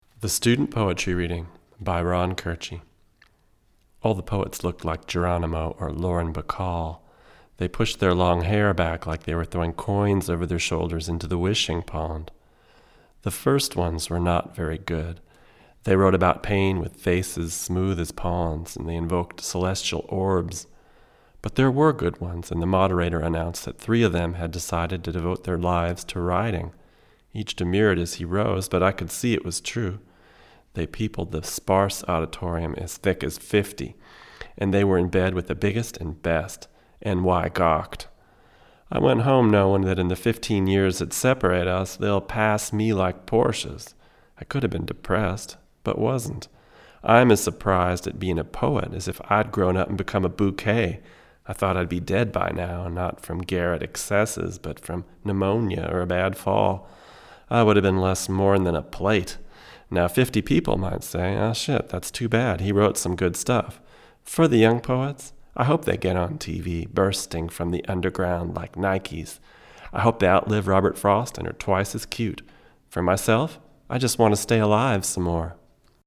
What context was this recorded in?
Here are 9 quick, 1-take MP3s using this C34 in a large room into a Audient Black preamp into a Sony PCM D1 flash recorder, with MP3s made from Logic. These tracks are just straight signal with no additional EQ, compresson or effects: